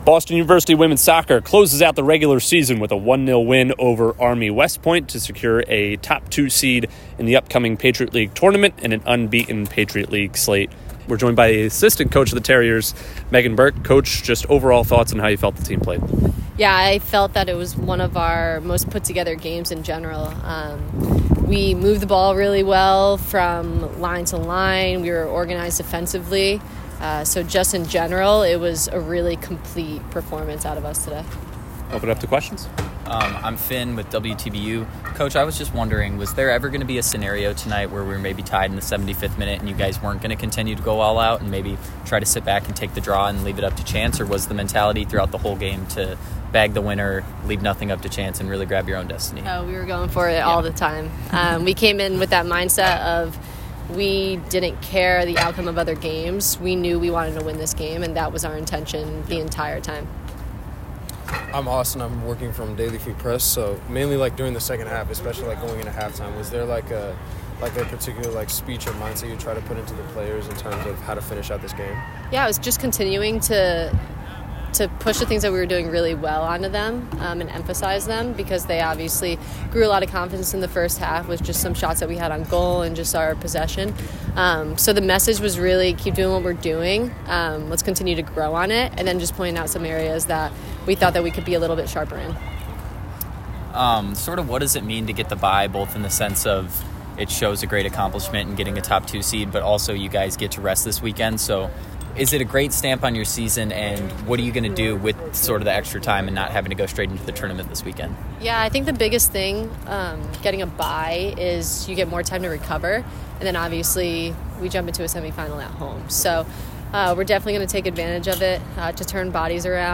WSOC_Army_Postgame.mp3